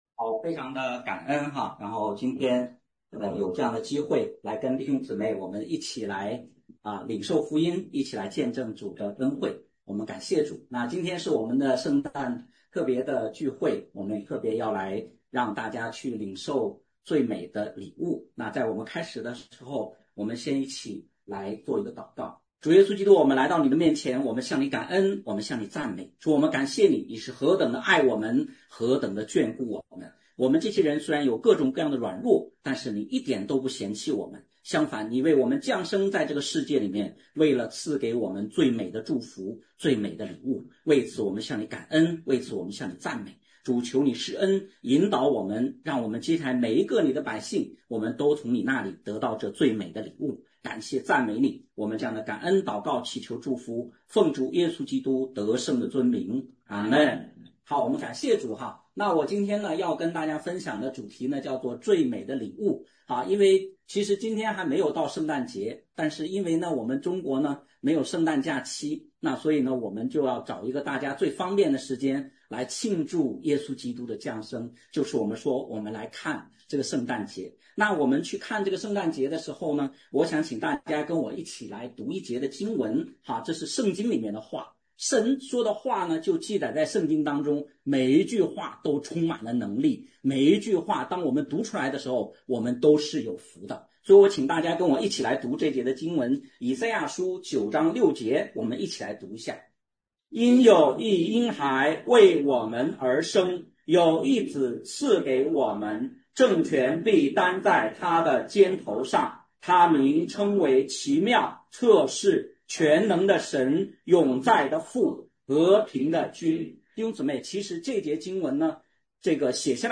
讲道录音 点击音频媒体前面的小三角“►”就可以播放： https